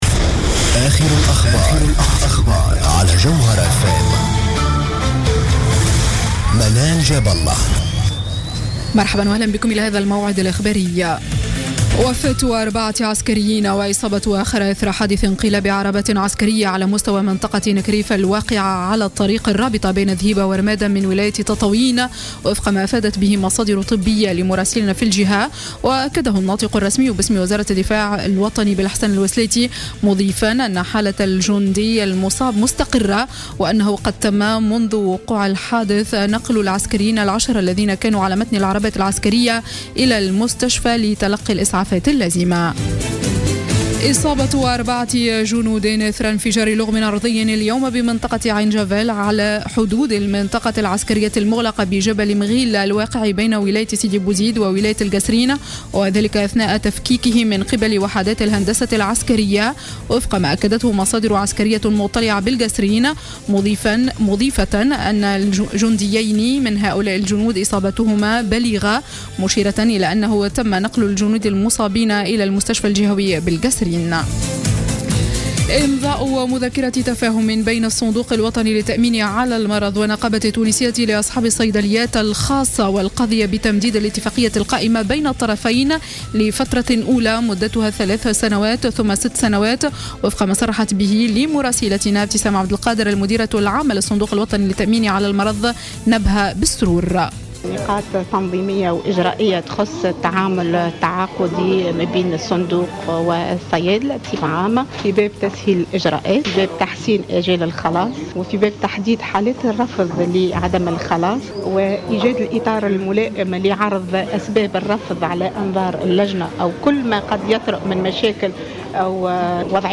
نشرة أخبار السابعة مساء ليوم الاثنين 17 أوت 2015